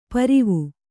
♪ parave